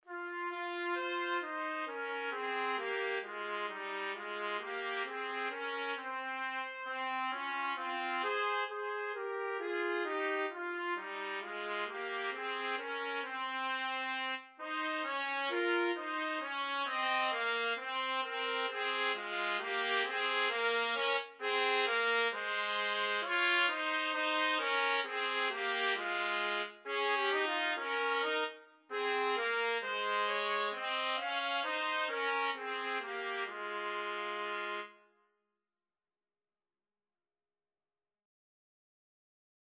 The melody is in the minor mode.
4/4 (View more 4/4 Music)
Slow two in a bar feel = c. 66
Trumpet Duet  (View more Easy Trumpet Duet Music)